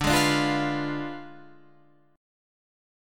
Ddim7 chord